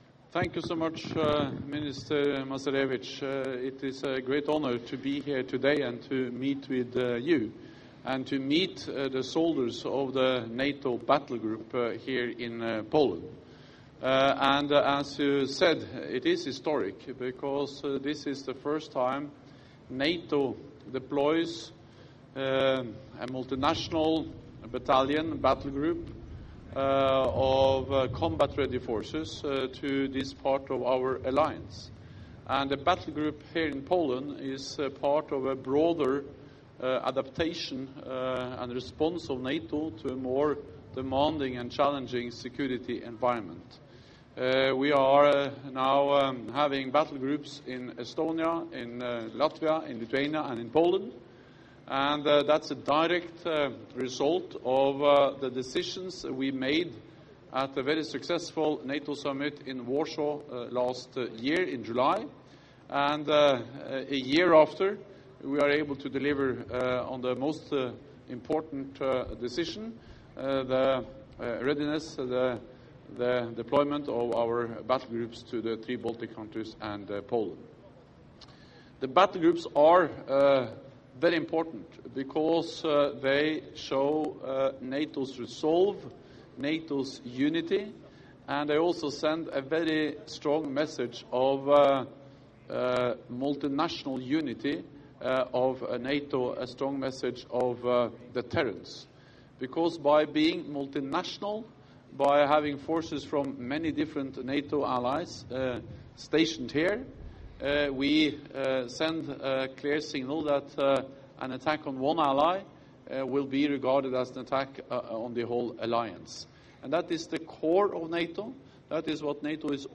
Joint press conference by NATO Secretary General Jens Stoltenberg and the Polish Minister of Defence, Antoni Macierewicz (Orzysz , Poland)
(As delivered)